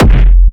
Industrial Techno Kick oneshot SC - F (14).wav
Nicely shaped, crispy and reverbed industrial techno kick, used for hard techno, peak time techno and other hard related genres.
industrial_techno_kick_oneshot_sc_-_f_(14)_cdr.ogg